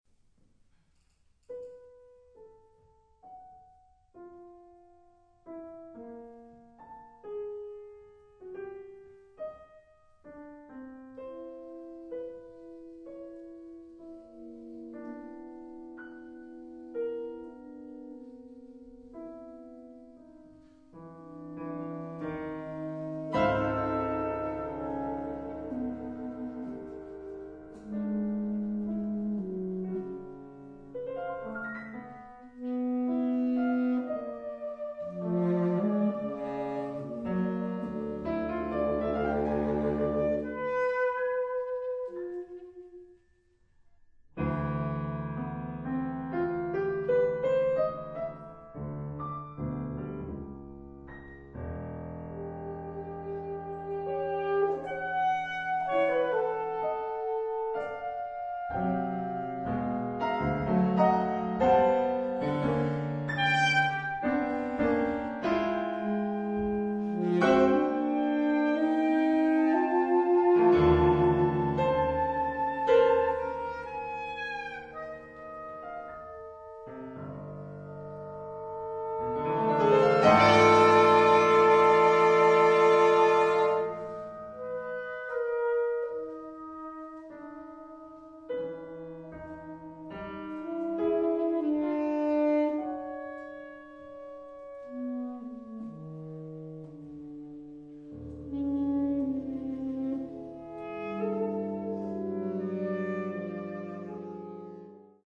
Partitions pour quatuor de saxophones, SATB + piano.